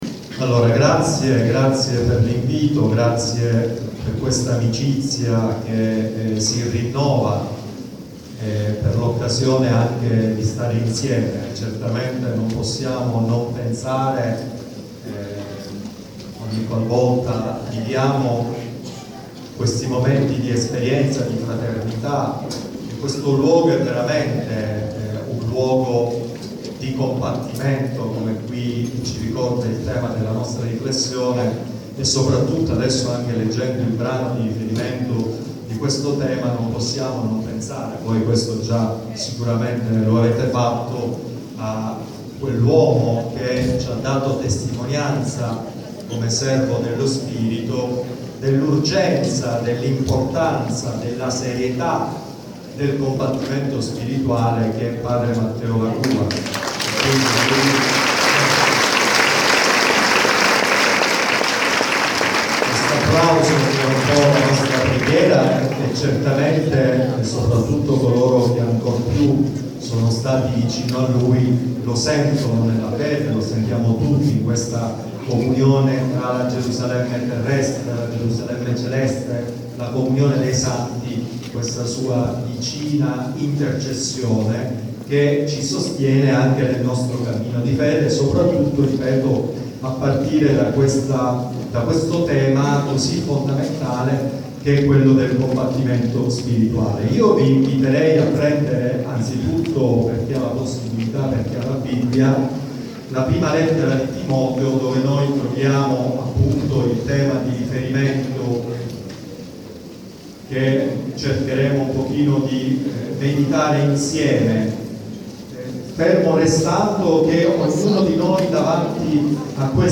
catechesi tenute al centro Gesù liberatore sul tema del combattimento spirituale
38° Convegno Diocesano Palermo RnS